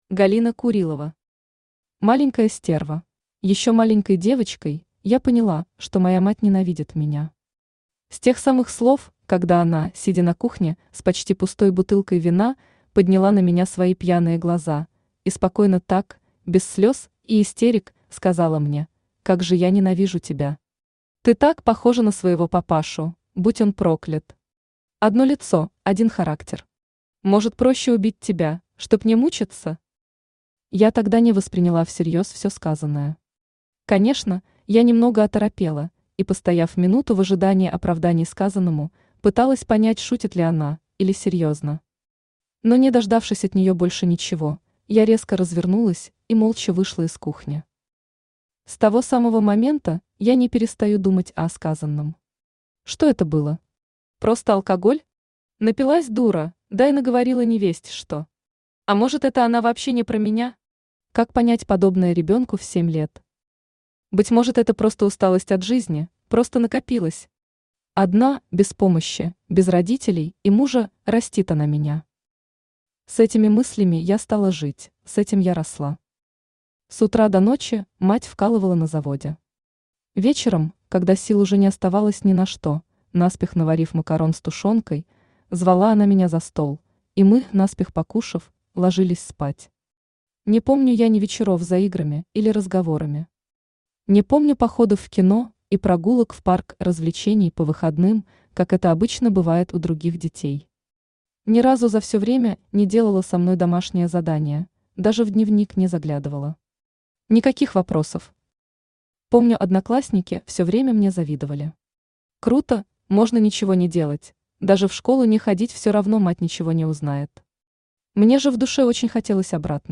Аудиокнига Маленькая стерва | Библиотека аудиокниг
Aудиокнига Маленькая стерва Автор Галина Евгеньевна Курилова Читает аудиокнигу Авточтец ЛитРес.